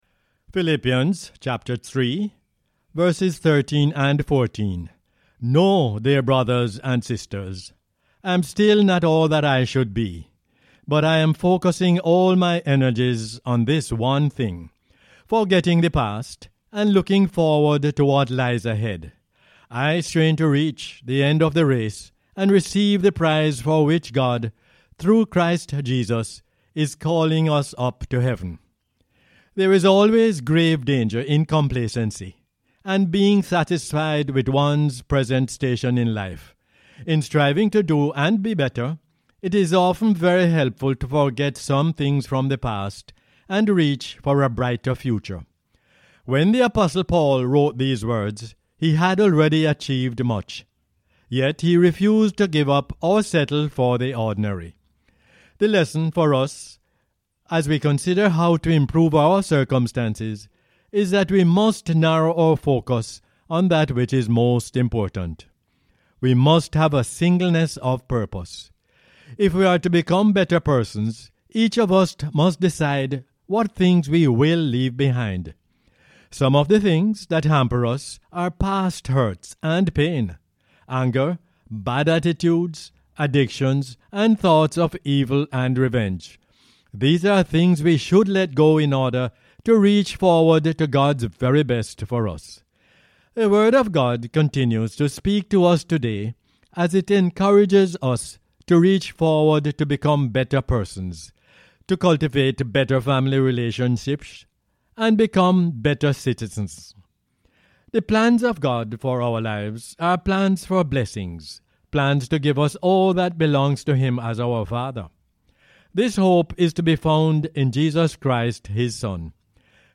Philippians 3:13-14 is the "Word For Jamaica" as aired on the radio on 11 November 2022.